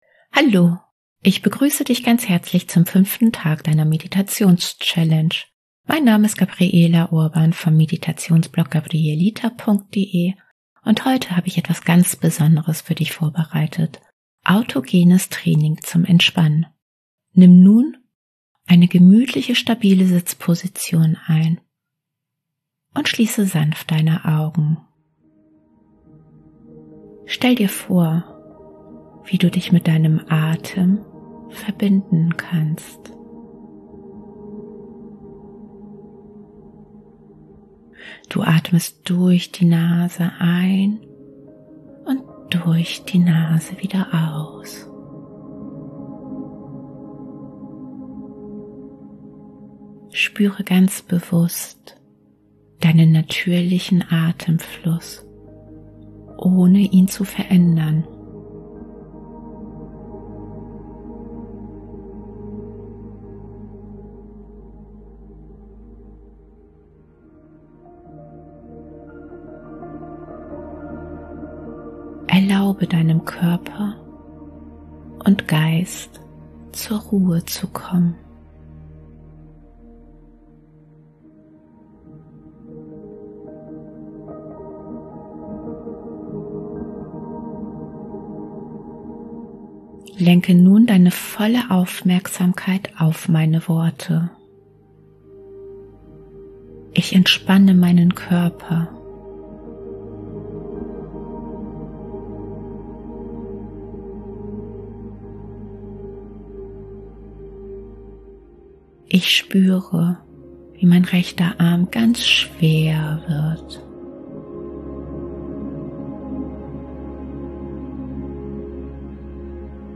Traumreisen & geführte Meditationen